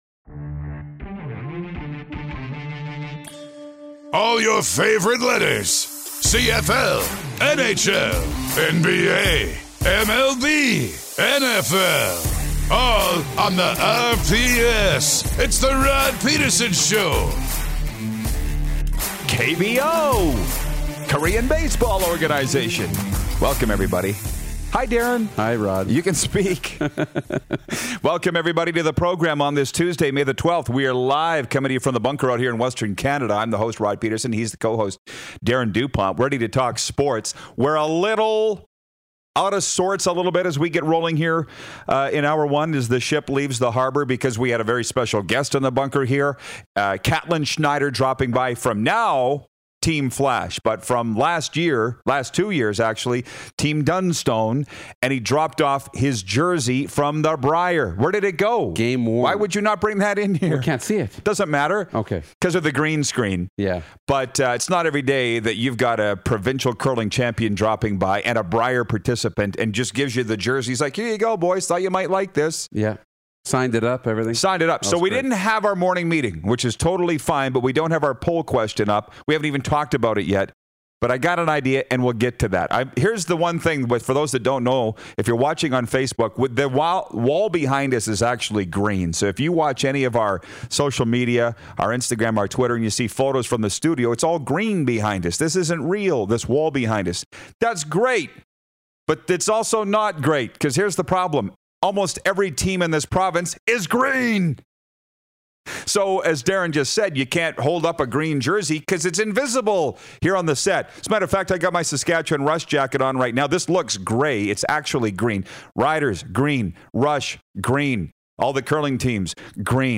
Here’s who we have today: 14 Year NHL Vet Matthew Barnaby is “Unfiltered” as he joins us on Video Chat!